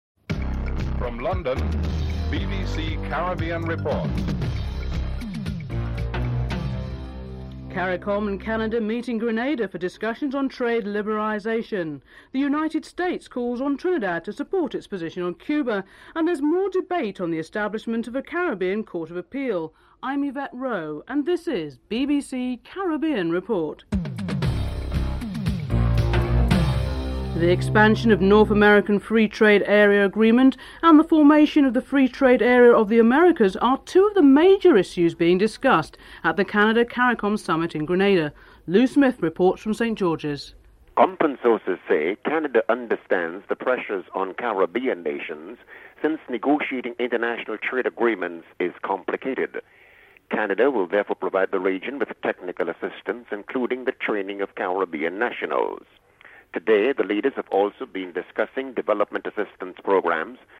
3. The United States calls on Trinidad and Tobago to support its position on Cuba. United States Secretary of State Warren Christopher is interviewed (02:15-05:18)
West Indies Captain Richie Richardson is interviewed (11:54-15:18)